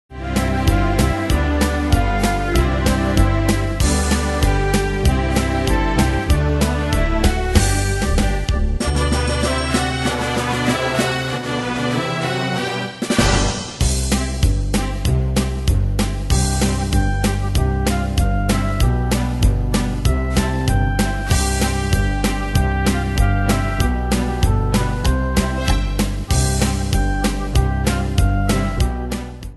Style: Oldies Ane/Year: 1965 Tempo: 96 Durée/Time: 3.29
Danse/Dance: Pop Cat Id.
Pro Backing Tracks